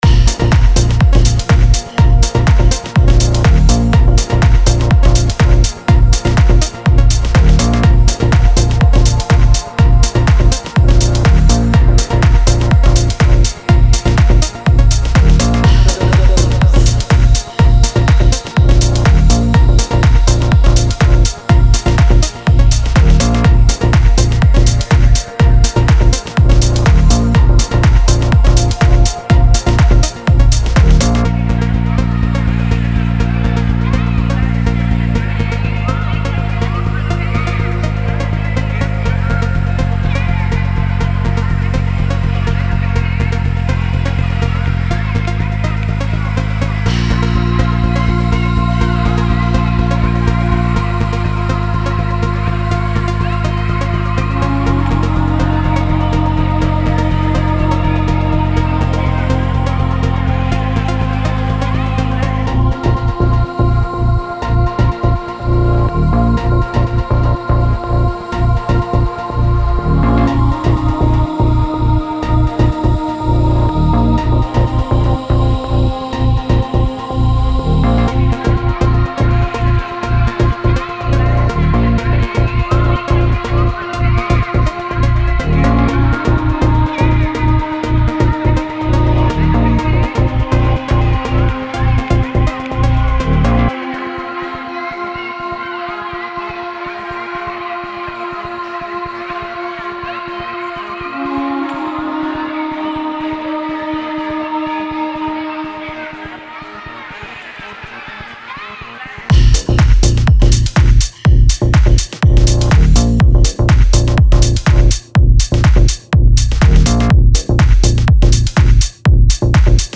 Написал простую тему трека, взял ударные из конструктор кита, взял бас из конструктор кита. Ушел час работы, не знаю как сведено, ибо в затычках.
Никто даже не заметит что низ трека из конструктора.